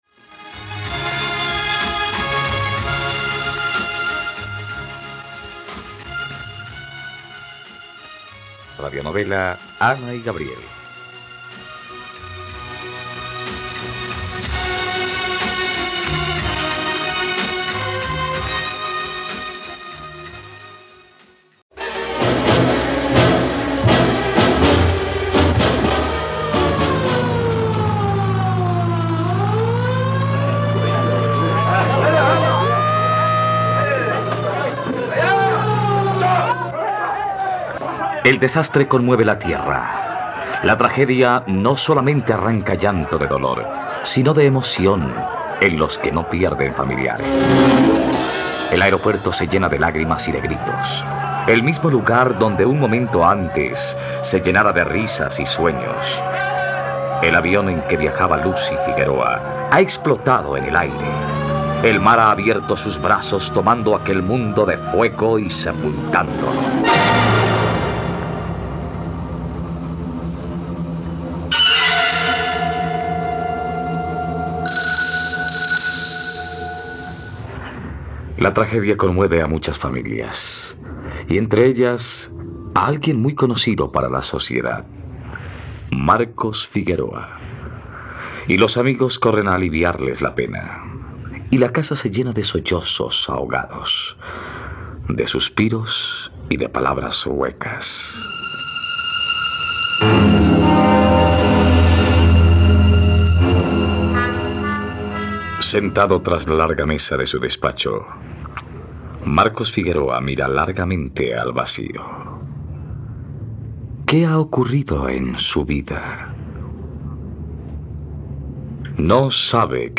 ..Radionovela. Escucha ahora el capítulo 127 de la historia de amor de Ana y Gabriel en la plataforma de streaming de los colombianos: RTVCPlay.